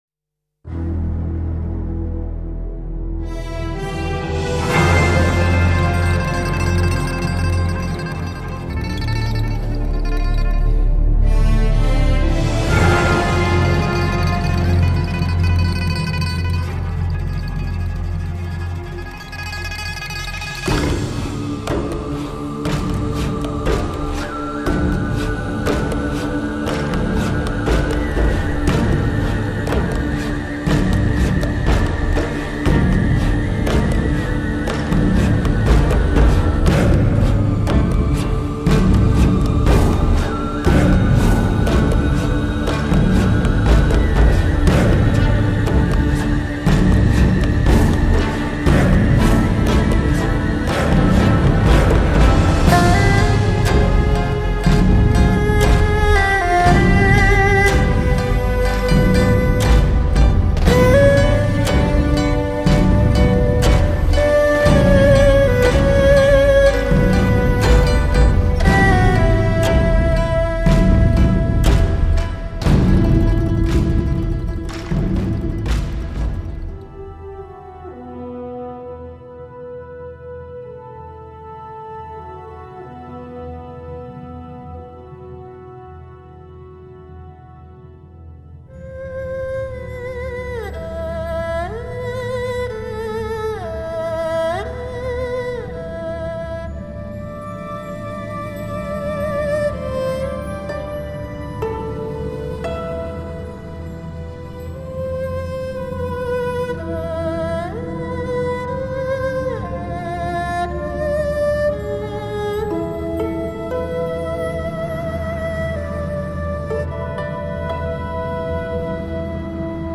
录音棚：中央电视台480平方米录音棚
古琴
扬琴
笛子
二胡
古筝
琵琶
马头琴